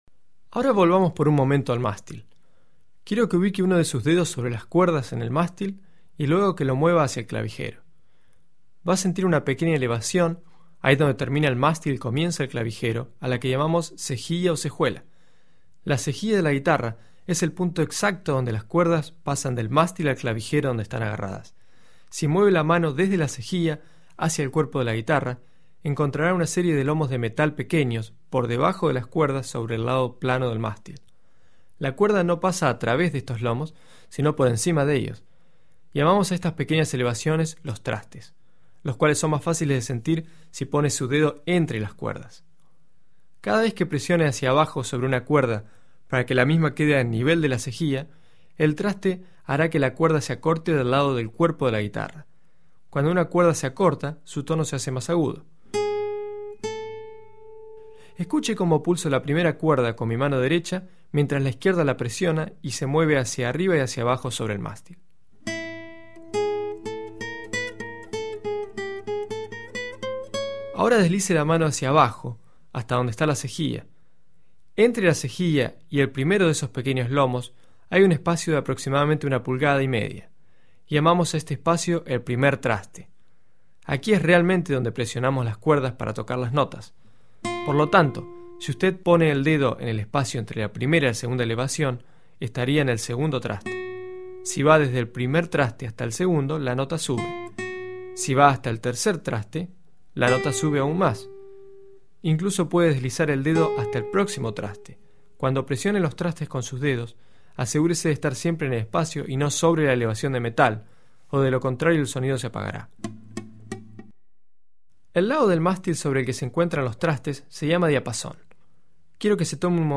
IntroGuitarraLessonSample.mp3